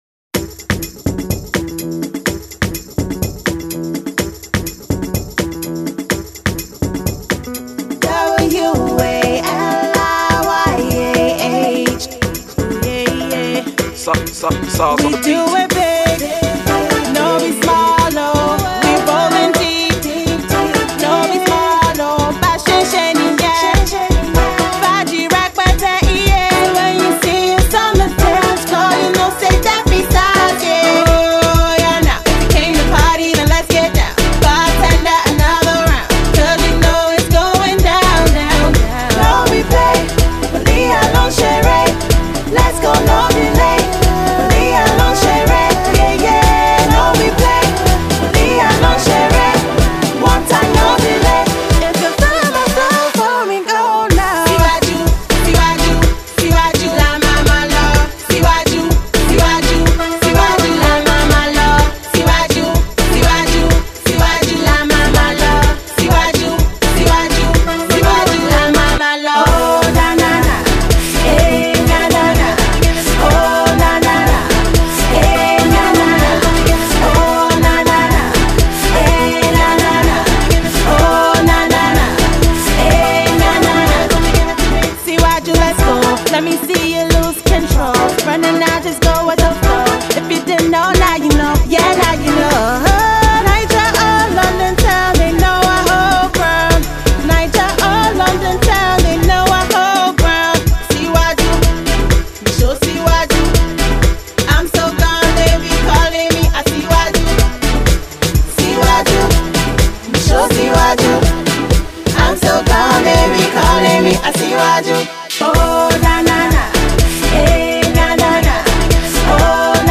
Uber-talented songstress
specially crafted to rock the dance floor